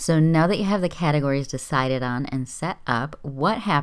Audio critique? It sounds like I'm talking in a box.
I’m recording on a USB mic, but I have it on a separate stand close to my face (and off the desk). My set up is in the corner of a small carpeted bedroom with furniture and lots of soft surfaces.
It doesn’t sound terrible, but it’s definitely not great.
It doesn’t show any signs of Windows Enhancements or room echoes.